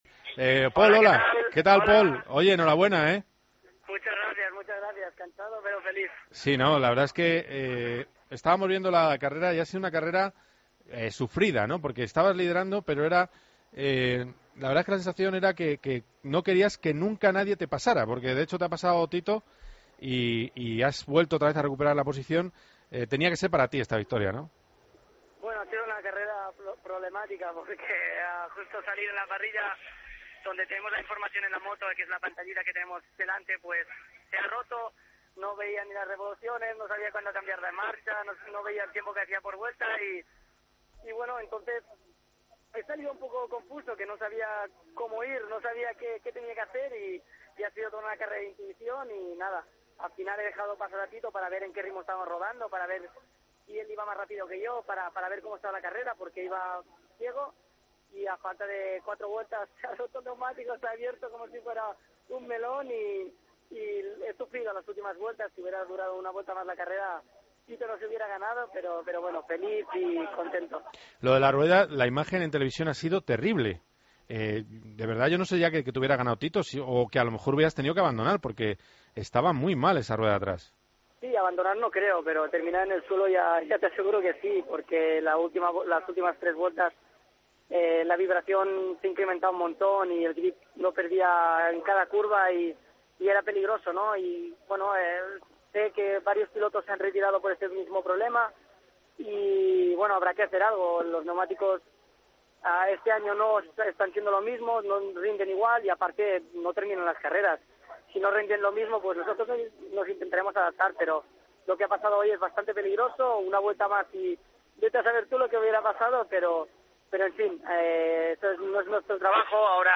Hablamos con el piloto español tras su triunfo en el GP de Cataluña:"Ha sido una carrera muy complicada desde el principio, pero he conseguido ganar, y estoy feliz".